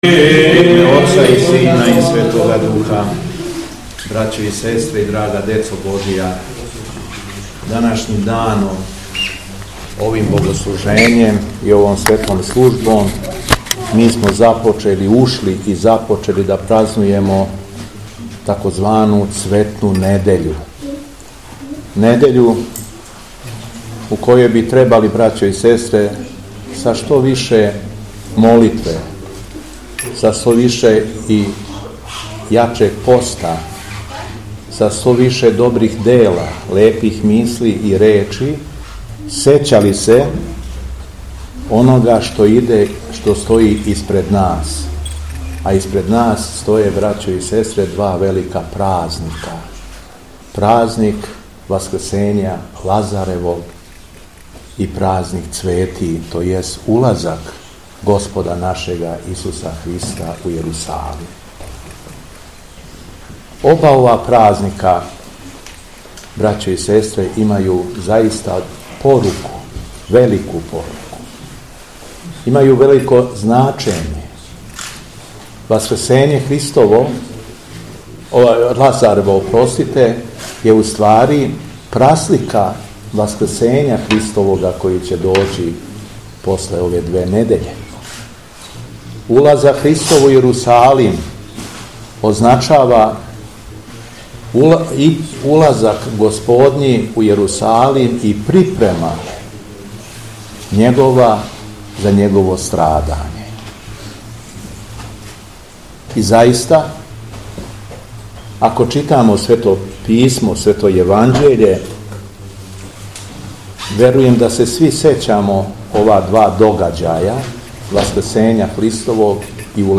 У понедељак, шесте недеље Часног поста, 22. априла 2024. године, Његово Епископ шумадијски Господин Г. Јован, служио је Свету Архијерејску Литургију Пређеосвећених Дарова у цркви Светог великомученика Георгија у Драгову.
Беседа Његовог Преосвештенства Епископа шумадијског г. Јована